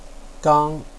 gāng
gang1.wav